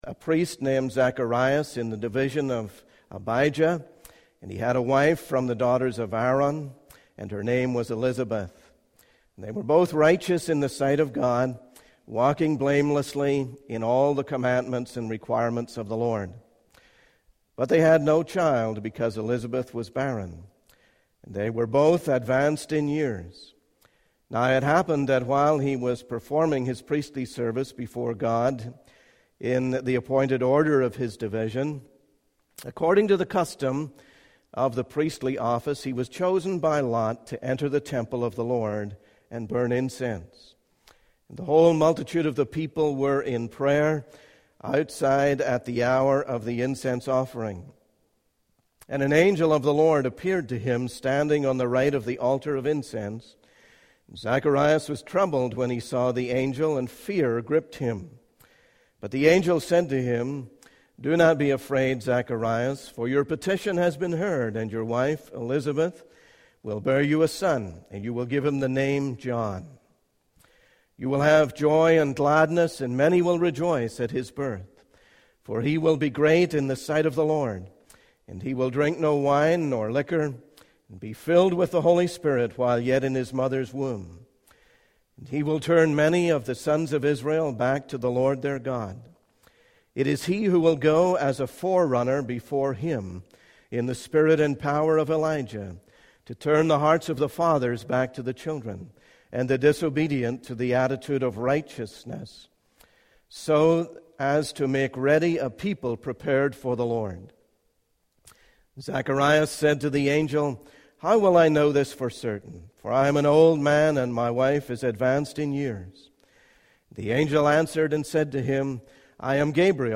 In this sermon, the speaker begins by apologizing for not being present at the previous week's Life Group presentation.